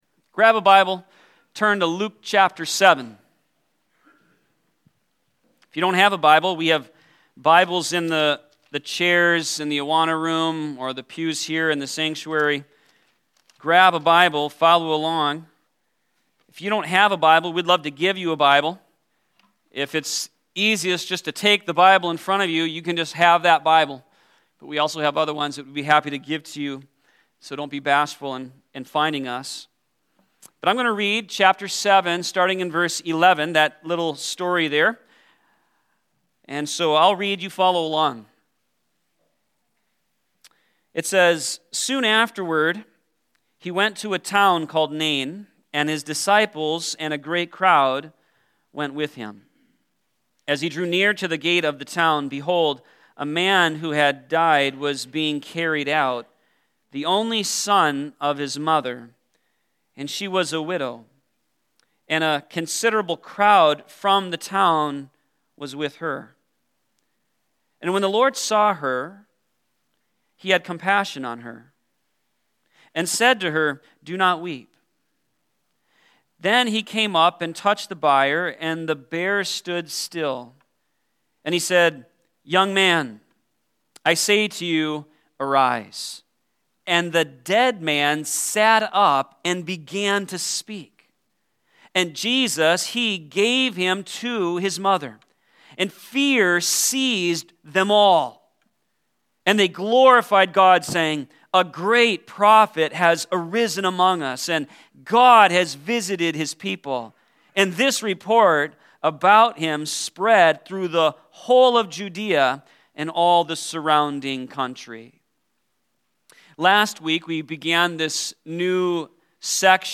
Sermon052216.mp3